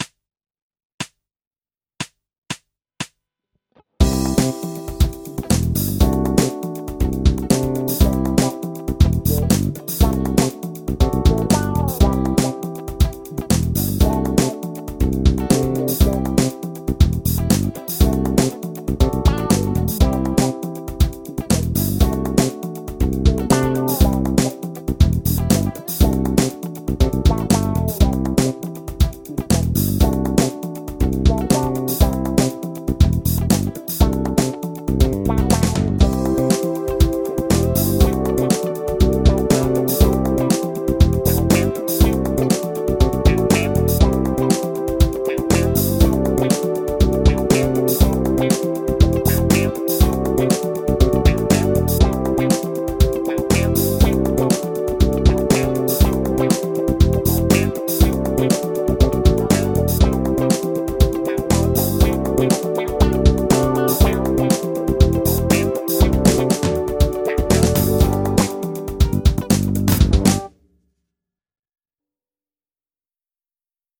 リディアン・スケール ギタースケールハンドブック -島村楽器